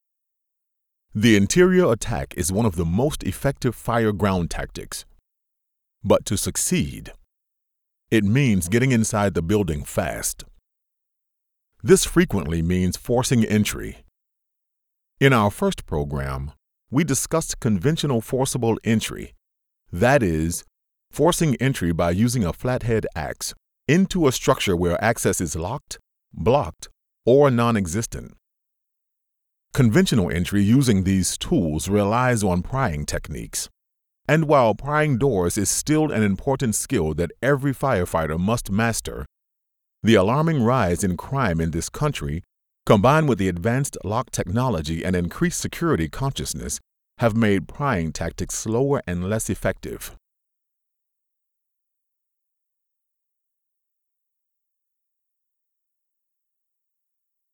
Male
Adult (30-50), Older Sound (50+)
E-Learning
1115E-Learning_Demo.mp3